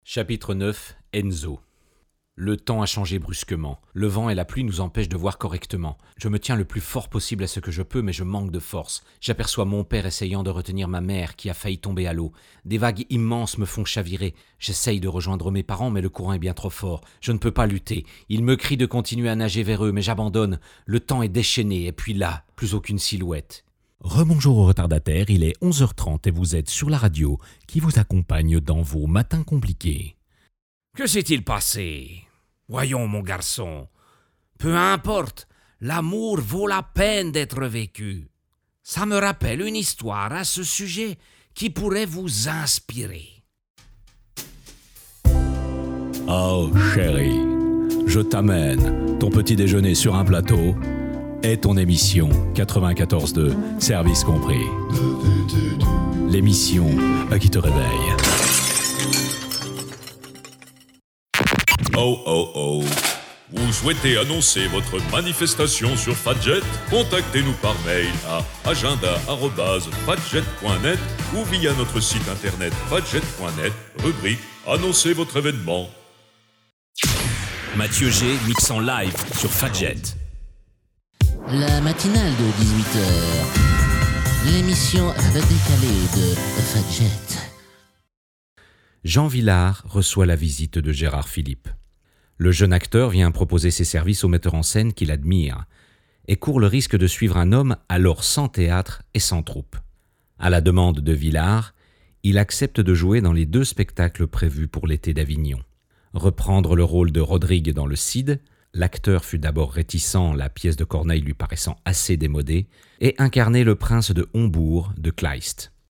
Voix off
demos radio et narration
16 - 65 ans - Baryton